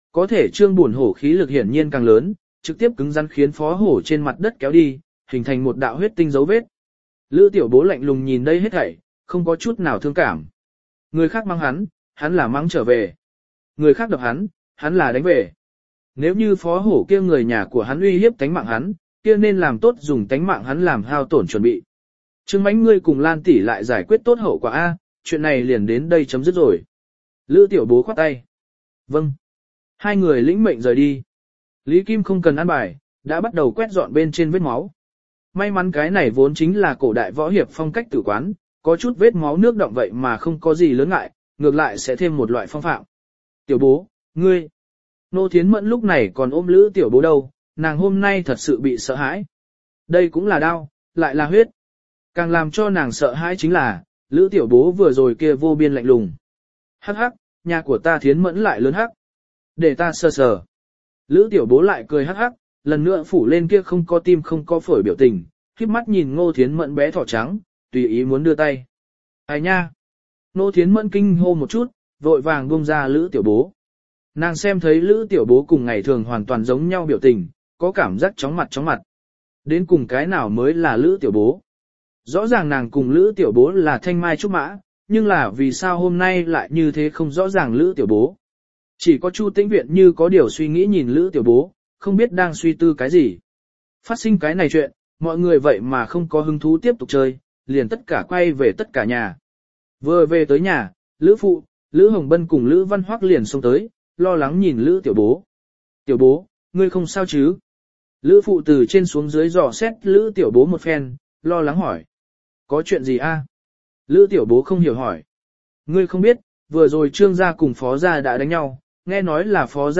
Phá Sản Hệ Thống Tại Hoa Đô Audio - Nghe đọc Truyện Audio Online Hay Trên TH AUDIO TRUYỆN FULL